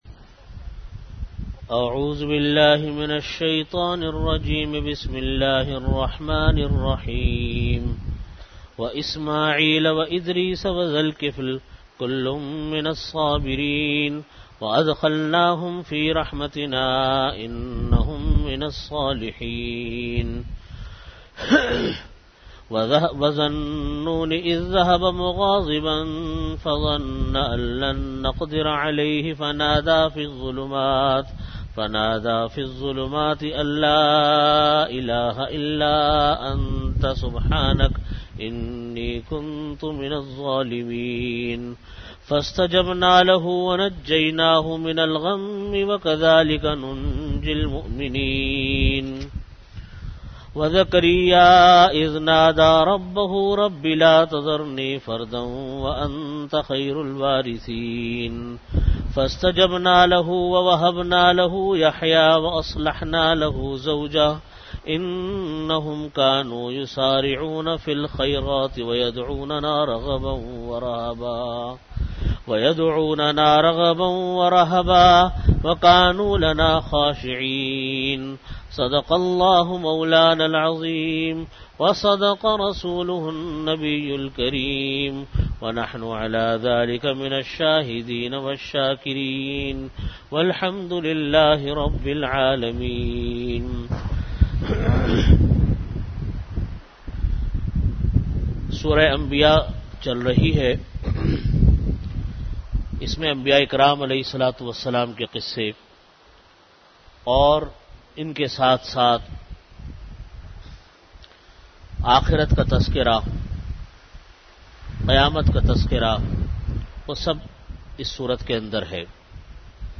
Audio Category: Bayanat
Time: After Asar Prayer Venue: Jamia Masjid Bait-ul-Mukkaram, Karachi